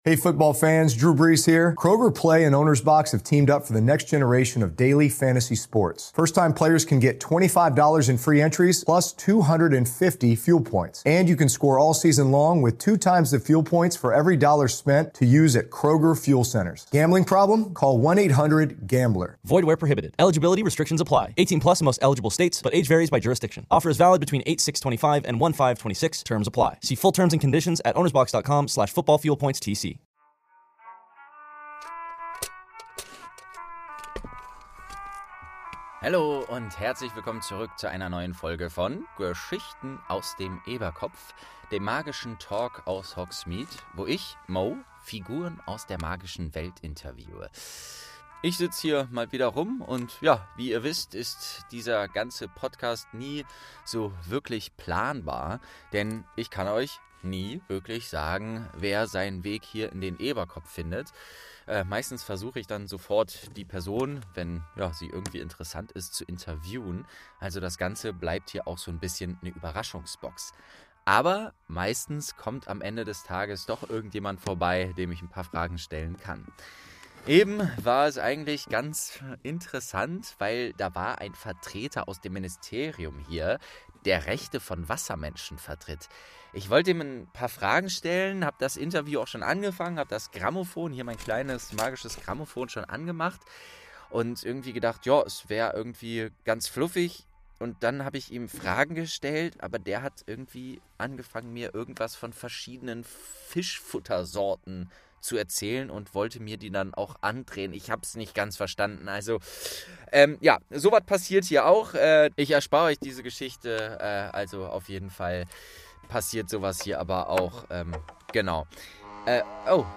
20. Prof. Sprout | St. 2 ~ Geschichten aus dem Eberkopf - Ein Harry Potter Hörspiel-Podcast Podcast